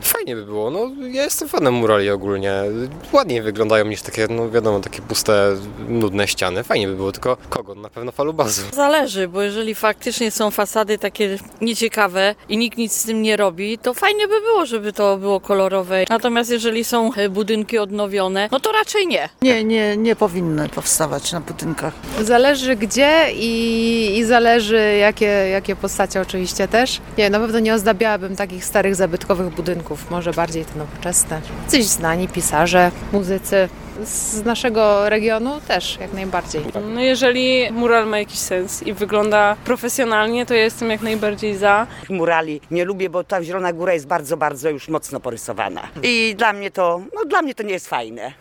[SONDA]
Dziś zapytaliśmy zielonogórzan, czy tego typu rysunki powinny powstawać w naszym mieście: